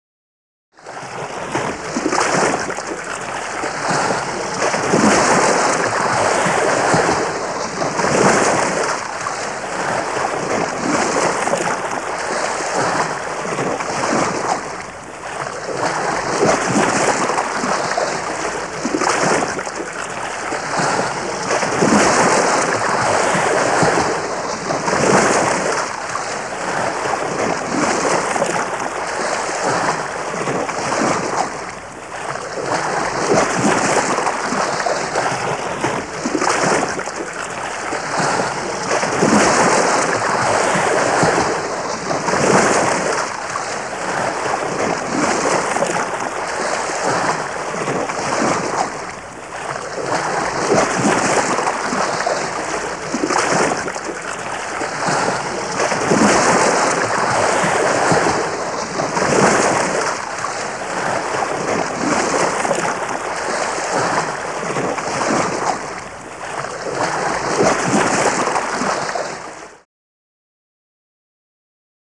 These sound effects were purchased from The Hollywood Edge under a license allowing our classroom and research use.